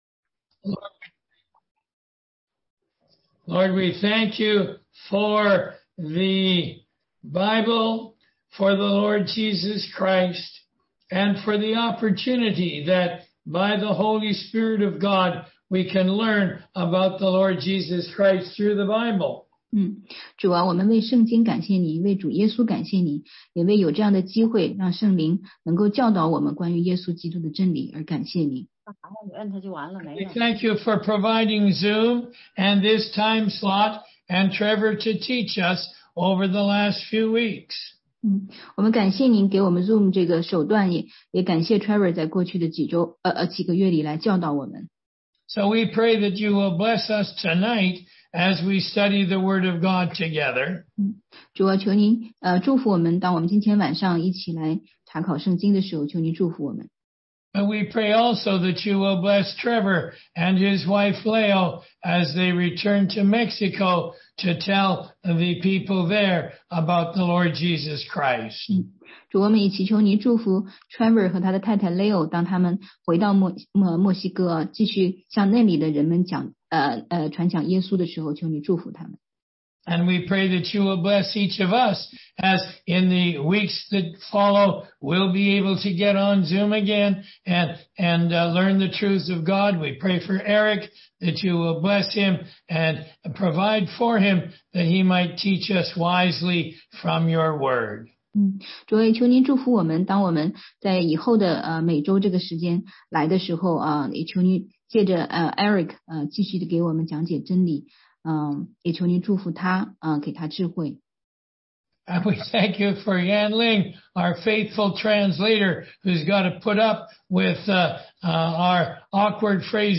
16街讲道录音 - 读经的方法和原则系列之十三：旧约预表的使用
中英文查经